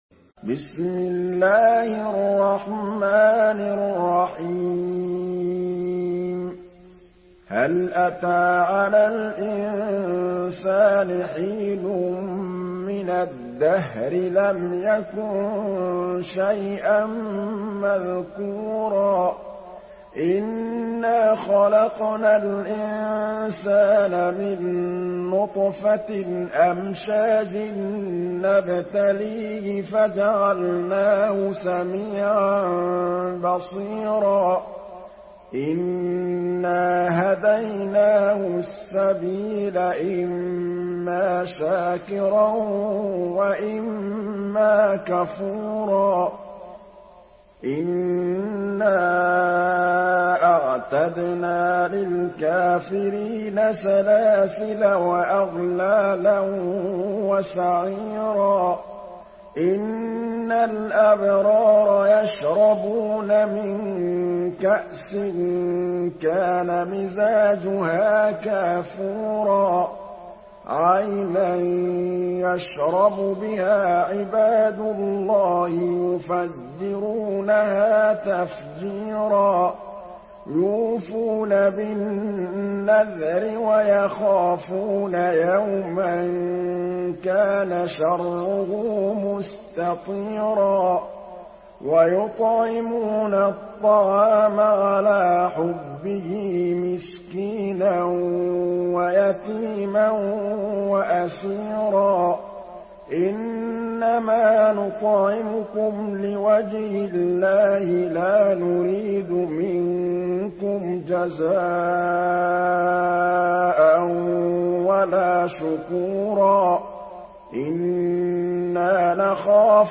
Surah আল-ইনসান MP3 by Muhammad Mahmood Al Tablawi in Hafs An Asim narration.
Murattal Hafs An Asim